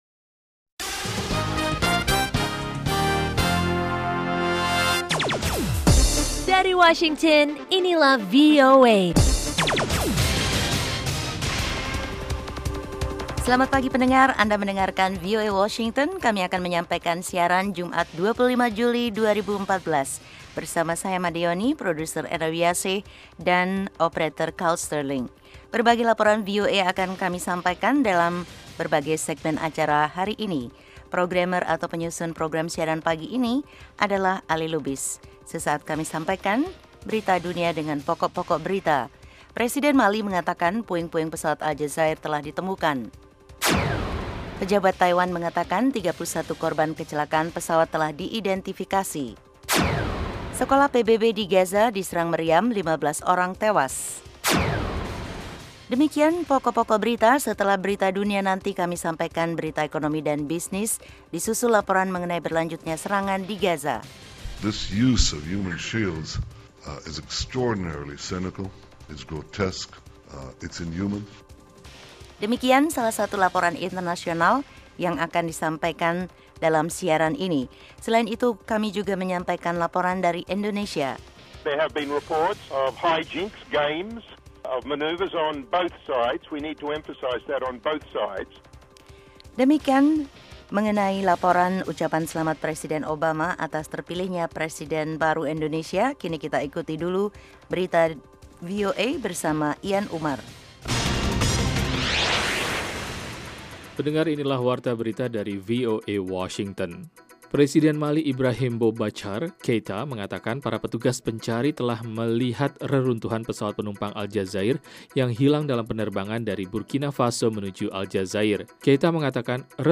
Setiap paginya, VOA menyiarkan sebuah program informatif yang menghidangkan beragam topik yang menarik, berita internasional dan nasional, tajuk rencana, bisnis dan keuangan, olah raga, sains dan kesehatan, musik dan tips-tips pengembangan pribadi.